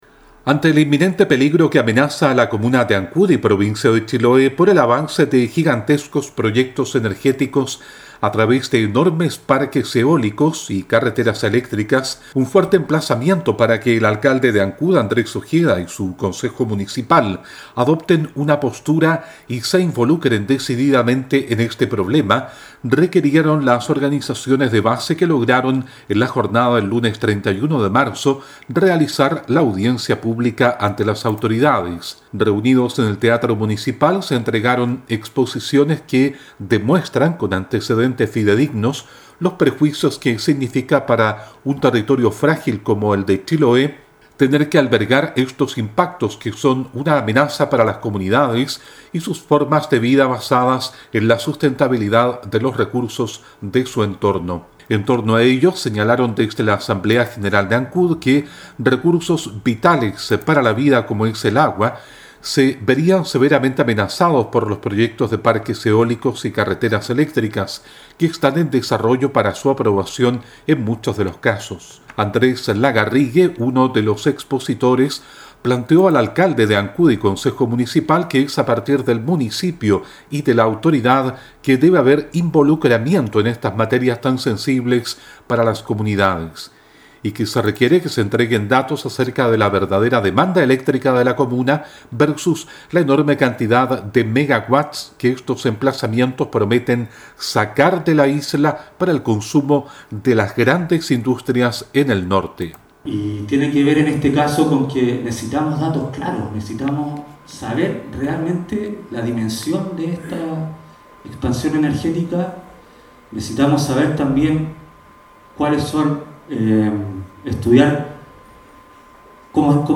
La audiencia pública realizada este lunes en Ancud puso de relieve la amenaza que significan para la comuna el desarrollo silencioso de los grandes proyectos de energía que están avanzando en la comuna y provincia de Chiloé en general.